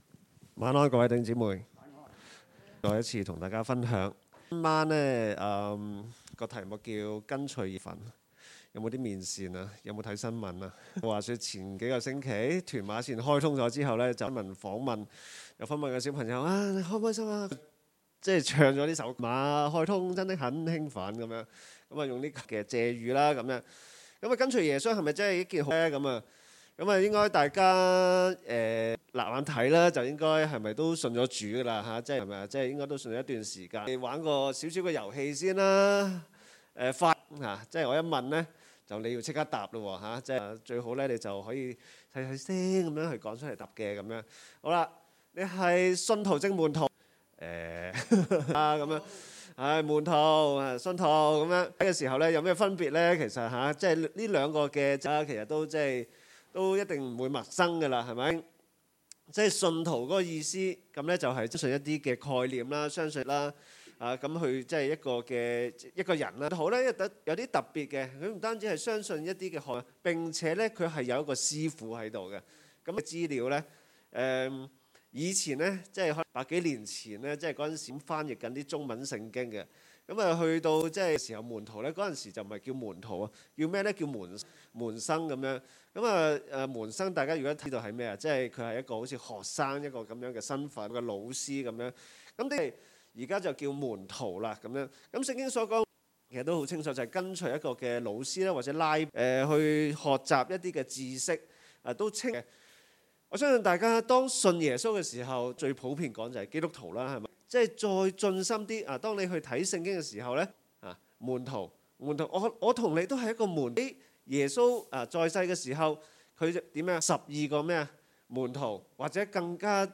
2021年7月18日晚堂信息